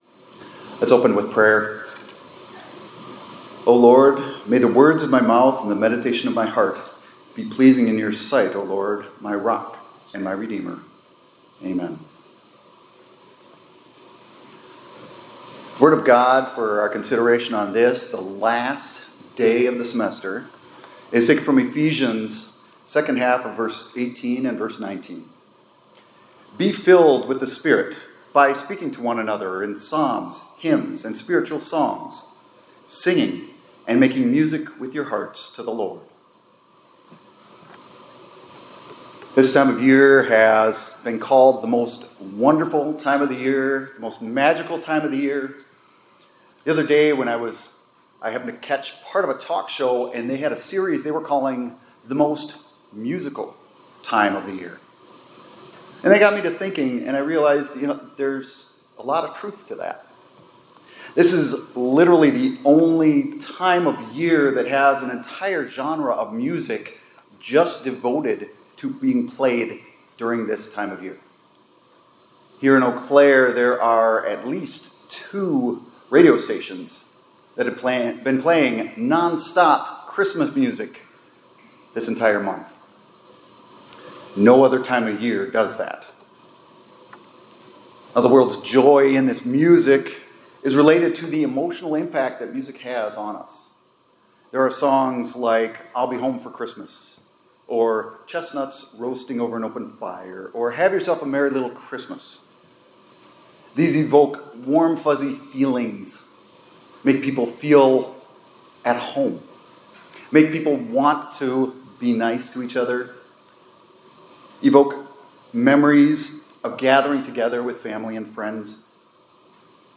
2025-12-19 ILC Chapel — It’s the Most Musical Time of the Year